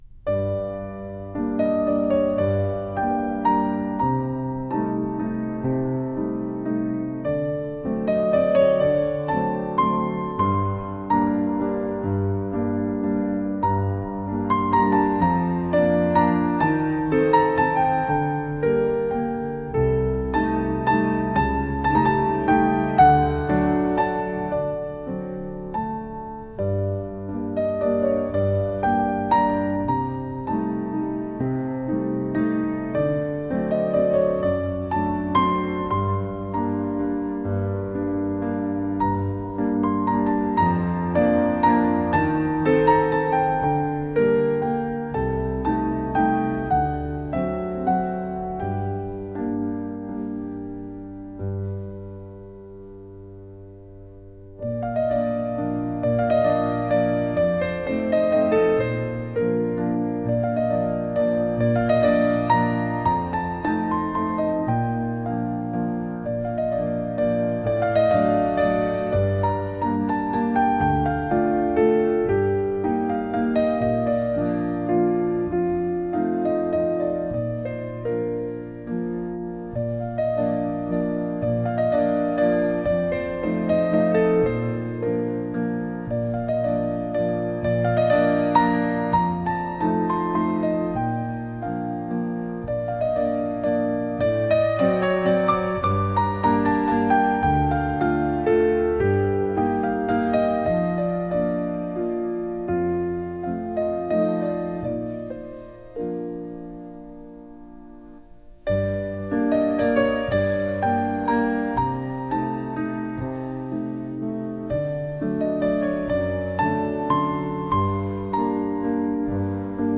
Flauto di Pan a aa strumenti musicali
MUSICA CLASSICA